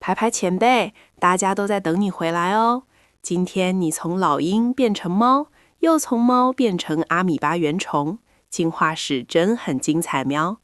add tts audio